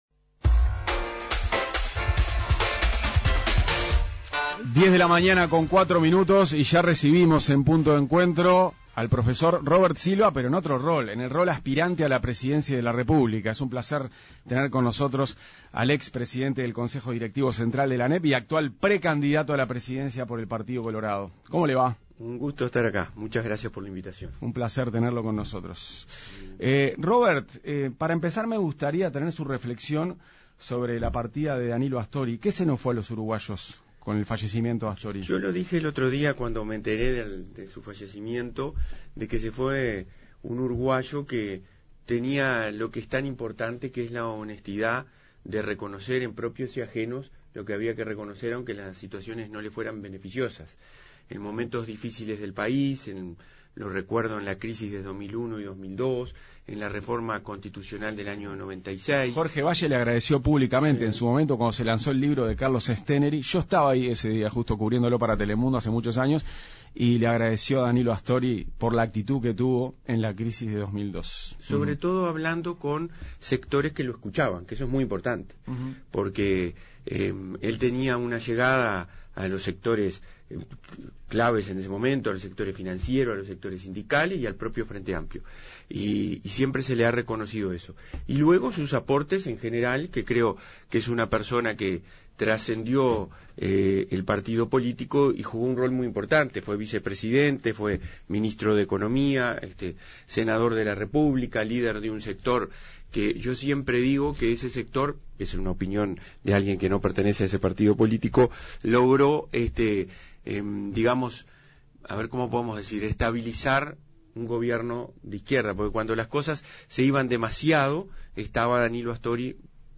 Entrevista-Robert-Silva.mp3